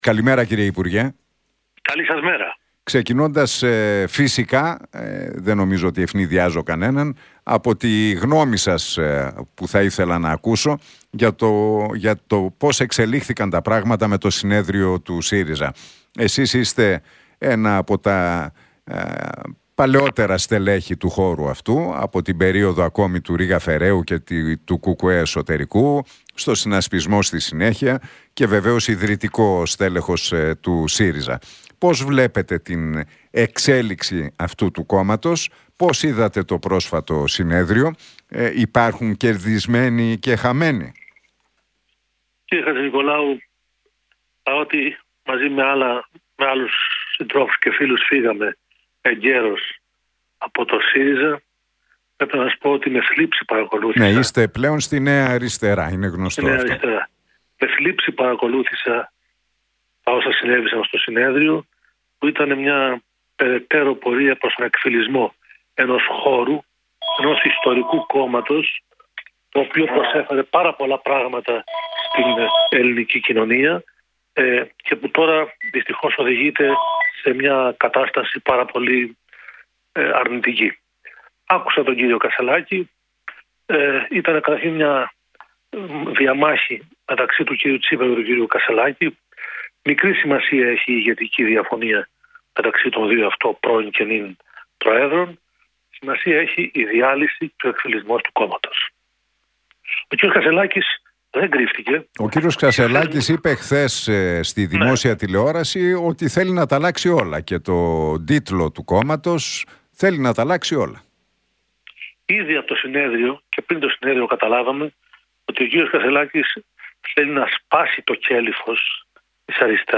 Ο Νίκος Φίλης, πρώην βουλευτής του ΣΥΡΙΖΑ και μέλος της Νέας Αριστεράς, δήλωσε μιλώντας στην εκπομπή του Νίκου Χατζηνικολάου στον Realfm 97,8 ότι «παρότι μαζί